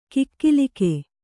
♪ kikkilike